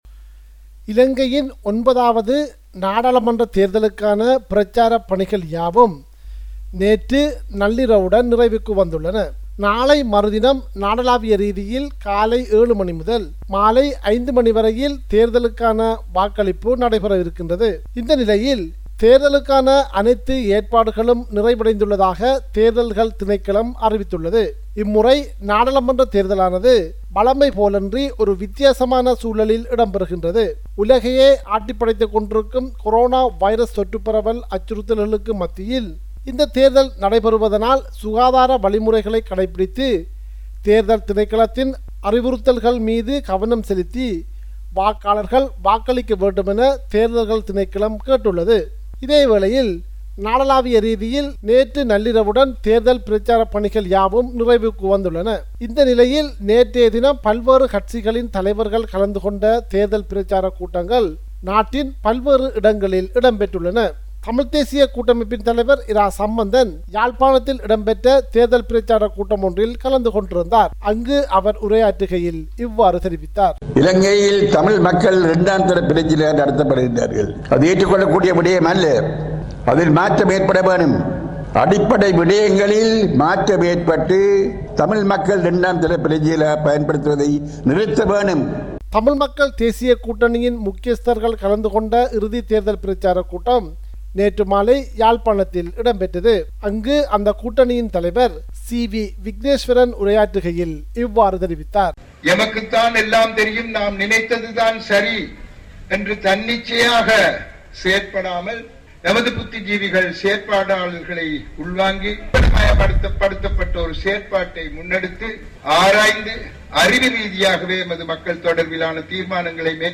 03.08_sbs_report_0.mp3